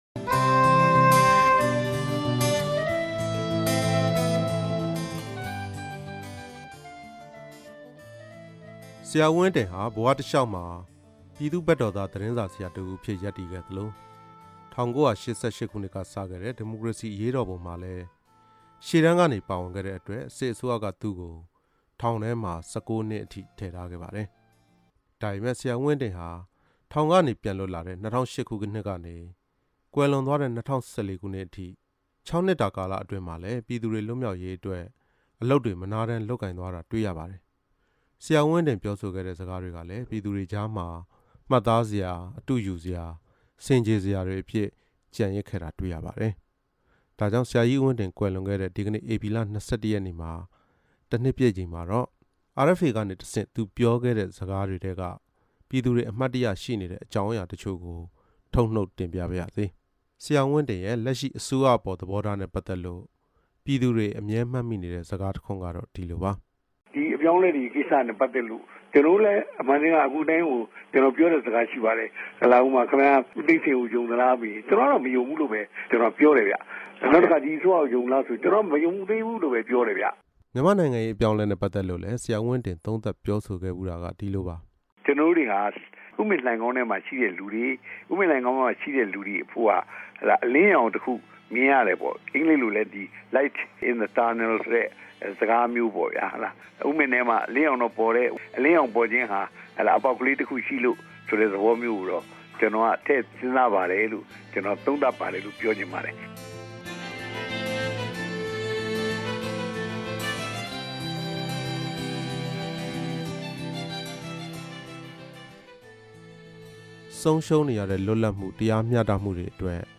ဟံသာဝတီဦးဝင်းတင် ပြောခဲ့တဲ့ စကားတချို့